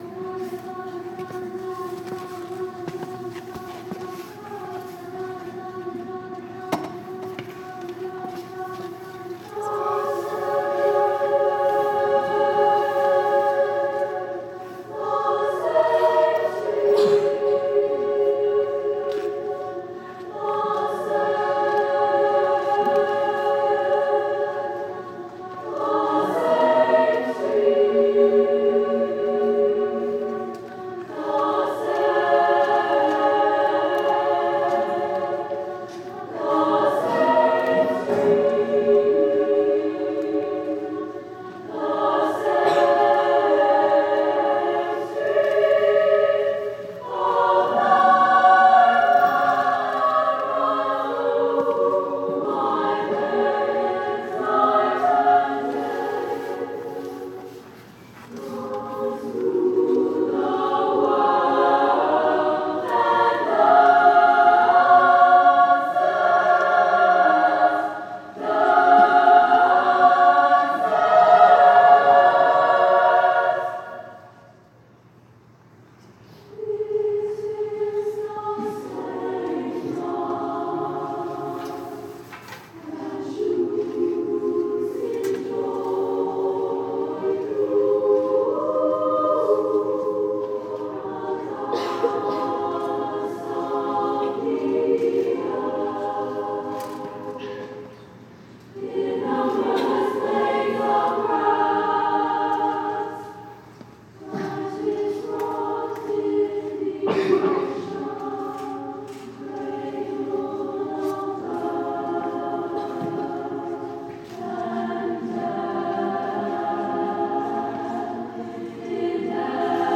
SSAA Chorus with Divisi